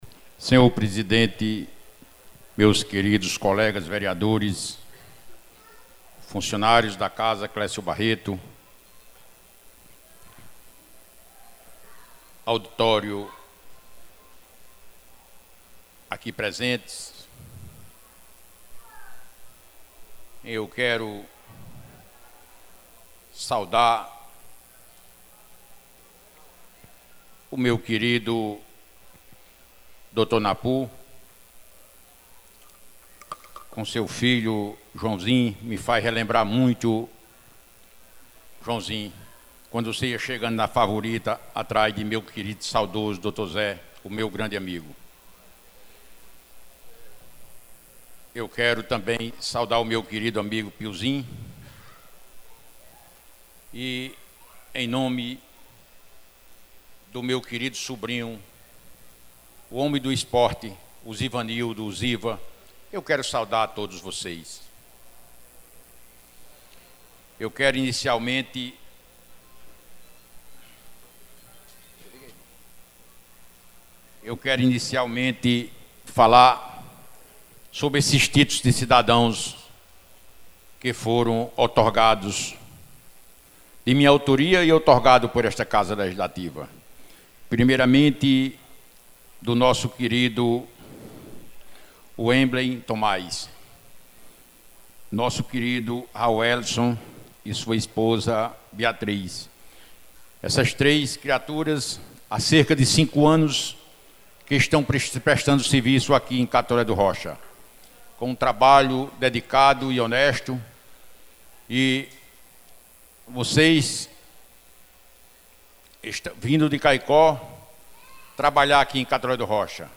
A declaração do vereador foi feita durante a 2ª Sessão Itinerante acompanhada da Ciranda de Serviços, na Escola Municipal de Ensino Infantil e Fundamental Prof. Catarina de Sousa Maia, localizada no Bairro Tancredo Neves, no último sábado, dia 12 de julho, em um momento de alerta sobre a grave estiagem que atinge diversas comunidades, resultando no esvaziamento de reservatórios, seca de cacimbões e prejuízos no abastecimento de água.
Confira abaixo a fala completa de Dr. Gentil Barreto, na íntegra, em nosso portal.
Pronunciamento-Vereador-Dr-Gentil-Barreto.mp3